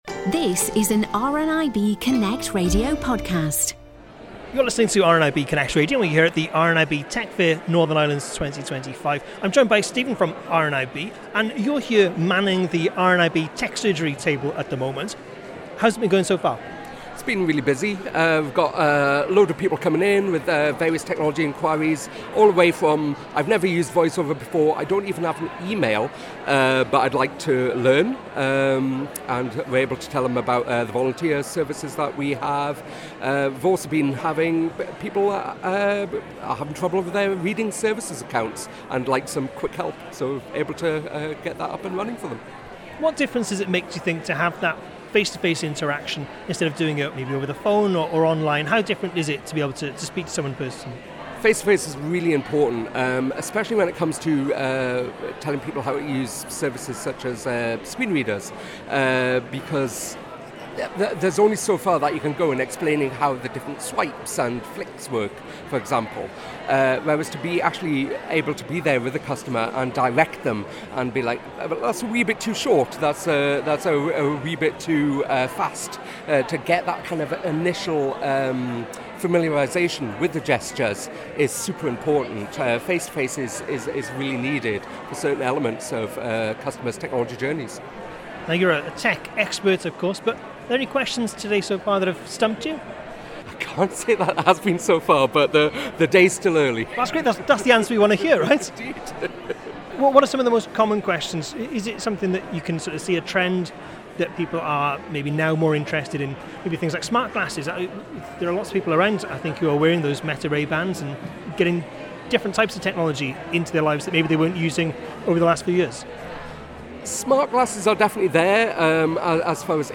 visited the RNIB ‘Tech Surgery’ table